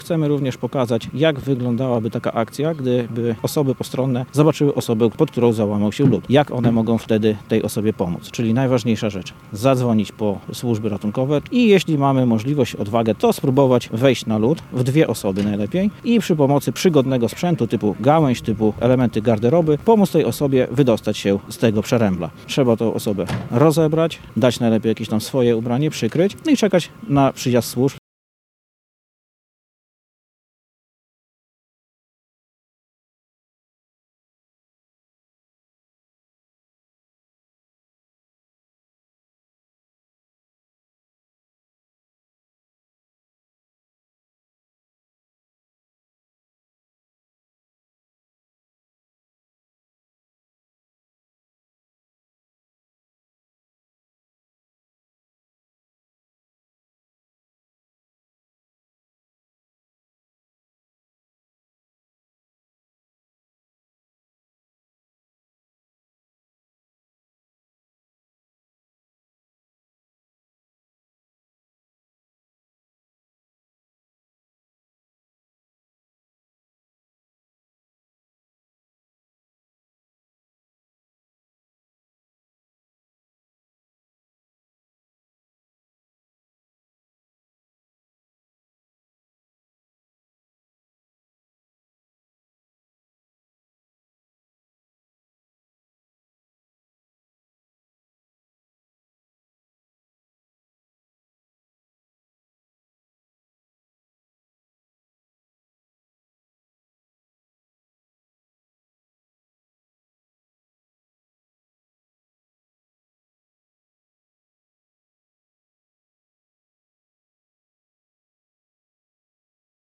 Na zalewie w Krasnobrodzie w sobotę (24.01) odbyły się pokazy ratownictwa na lodzie.